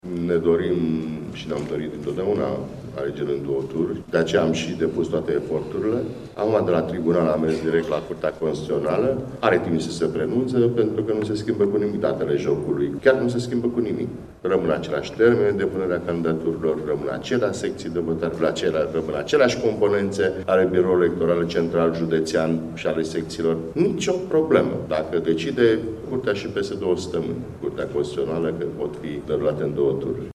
Copreşedintele PNL, Vasile Blaga, a reafirmat astăzi, la Iaşi, dorinţa liberalilor ca alegerile locale să se desfăşoare în două tururi.